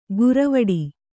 ♪ guravaḍi